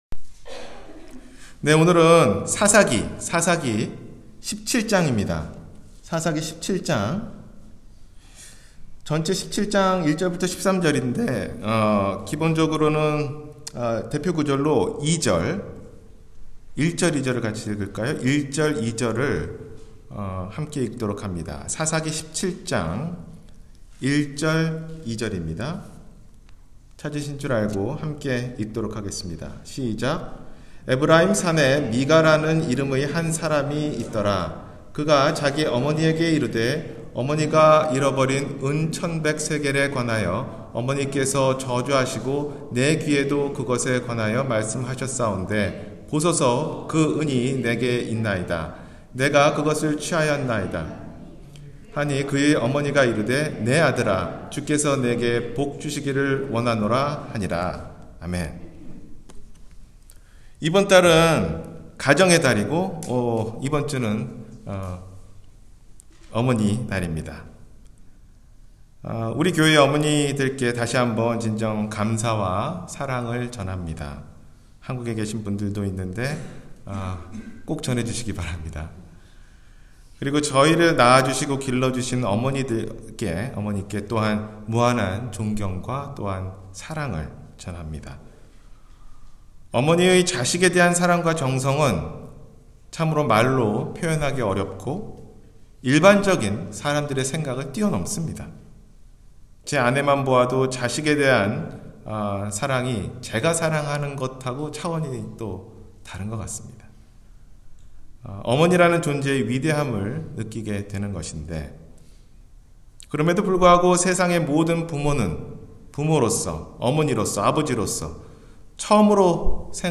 부모로서 하나님 앞에서-주일설교